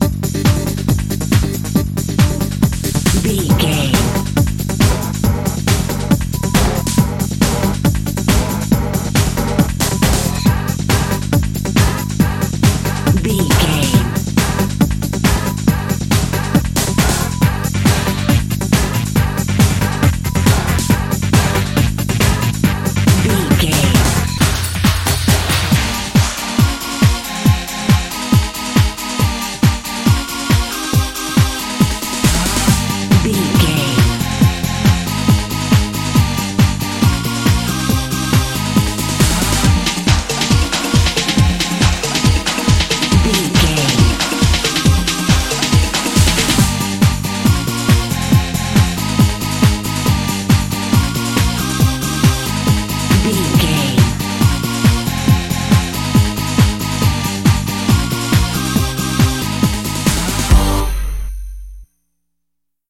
Uplifting
Aeolian/Minor
Fast
drum machine
synthesiser
electric piano
Eurodance